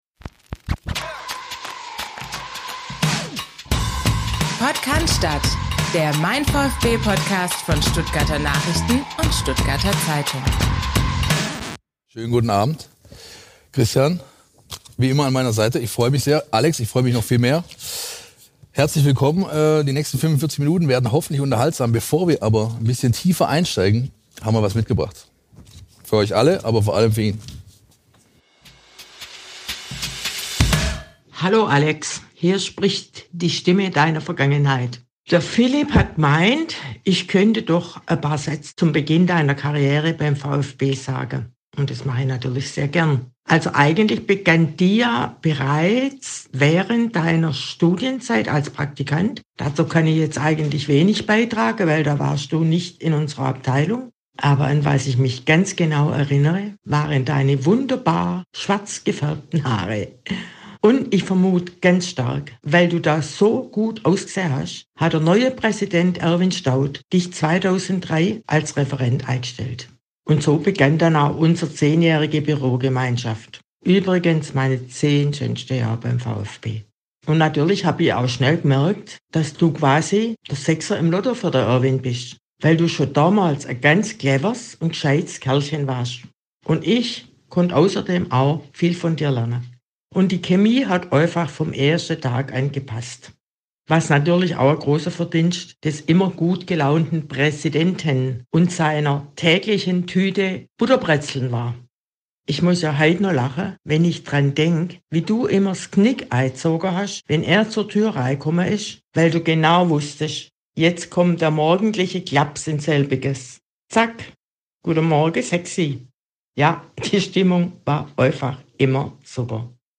Live-Event